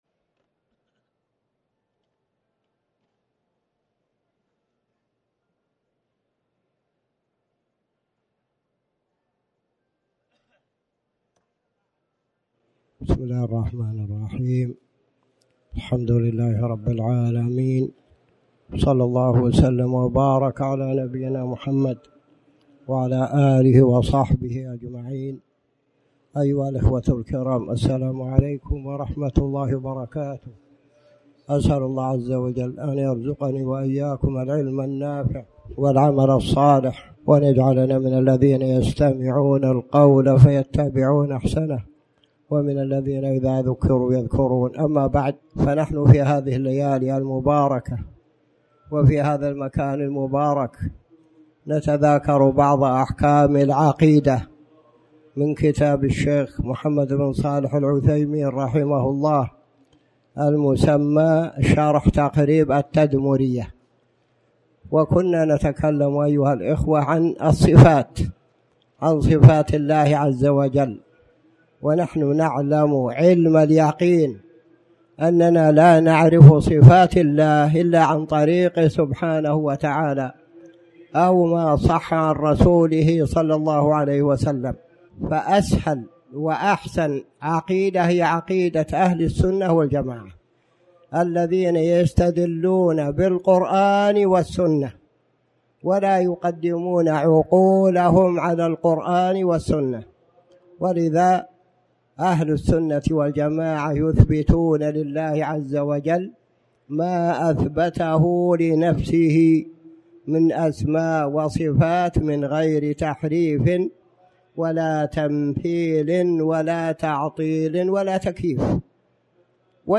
تاريخ النشر ٨ ذو القعدة ١٤٣٩ هـ المكان: المسجد الحرام الشيخ